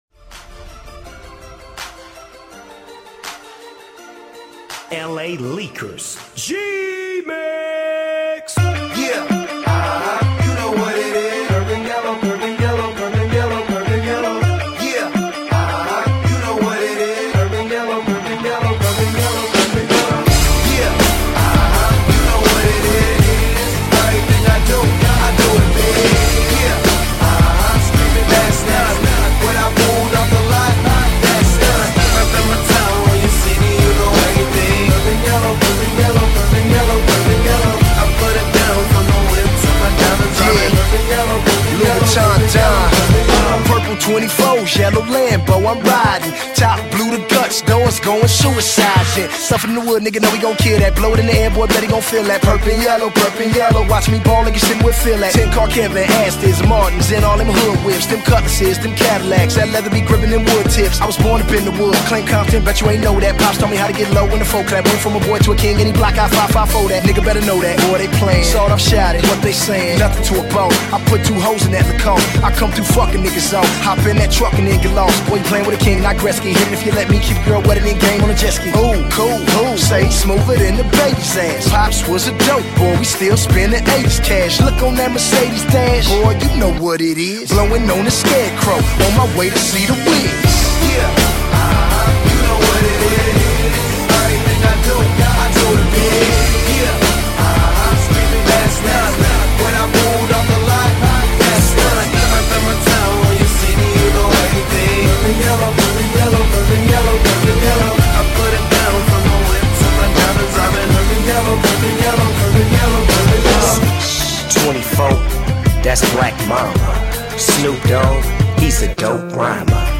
Категория: Hip-Hop - RAP